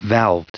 Prononciation du mot valved en anglais (fichier audio)
Prononciation du mot : valved